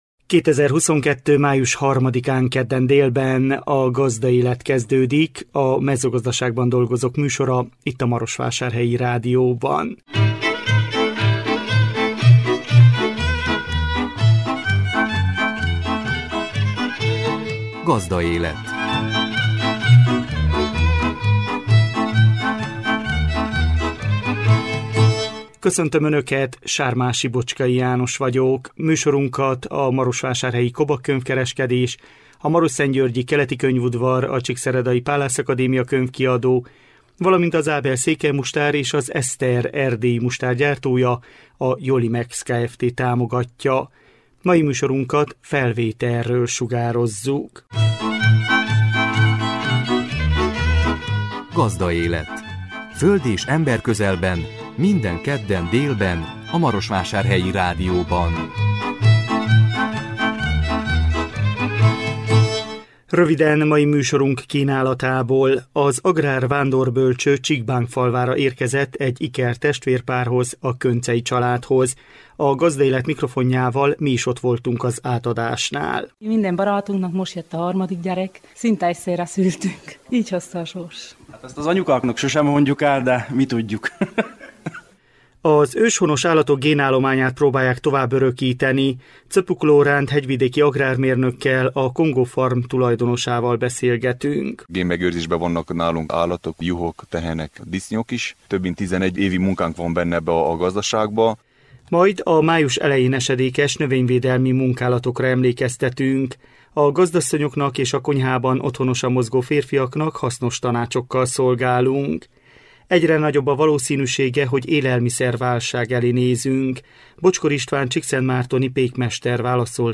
A Gazdaélet mikrofonjával mi is ott voltunk az átadásnál. Az őshonos állatok génállományát próbálják továbbörökíteni.